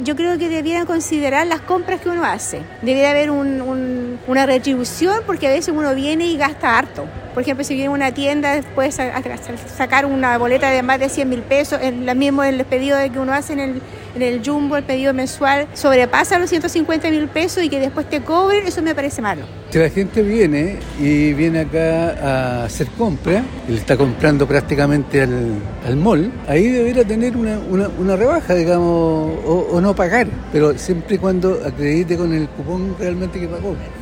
Sin embargo, sigue en la discusión la posibilidad de una rebaja dependiendo de la compra que cada persona realice, así lo comentaron algunos clientes.